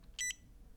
Звуковые сигналы | PiData wiki